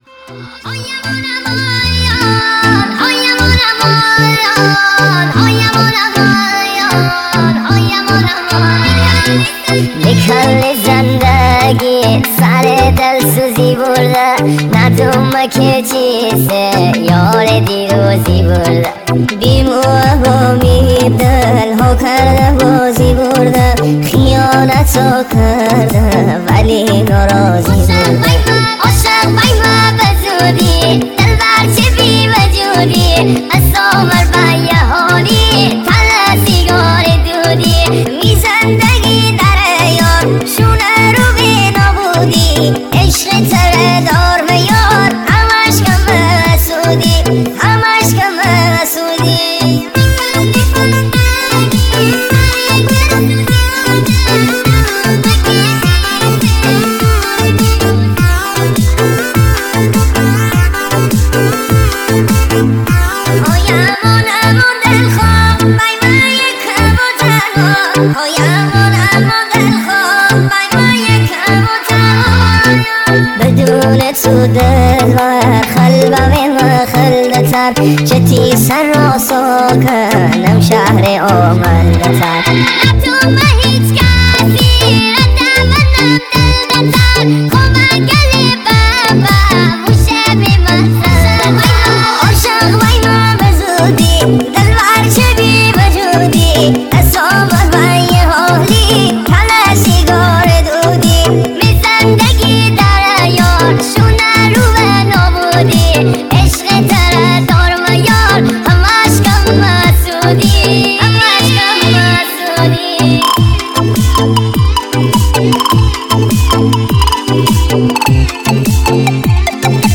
صدای زن دختر نازک بچگانه